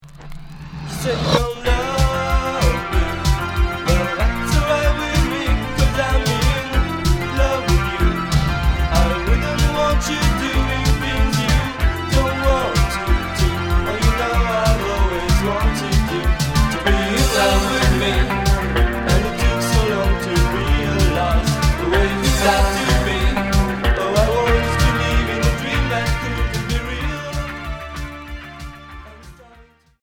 New wave